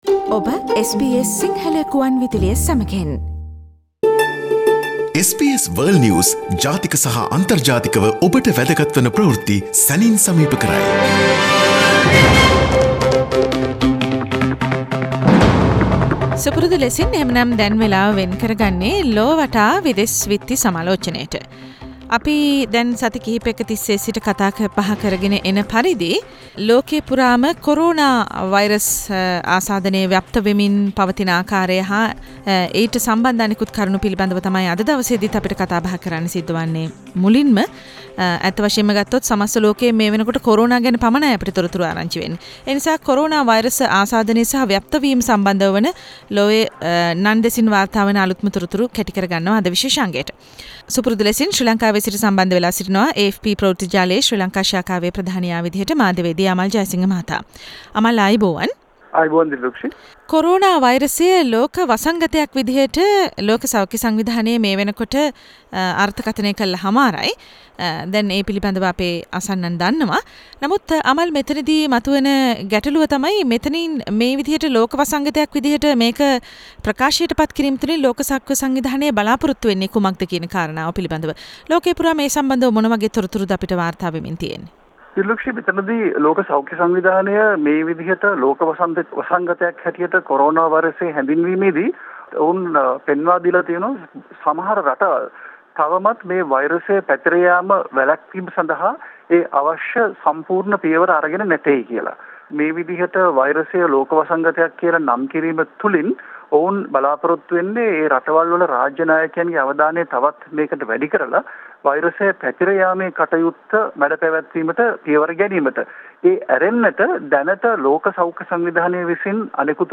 world news wrap - Source: SBS Sinhala radio